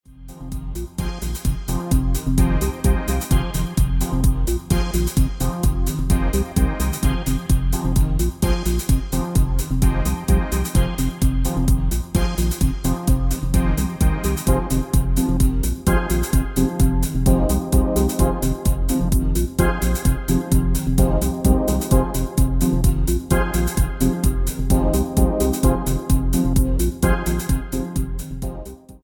Dance/Electronic
Techno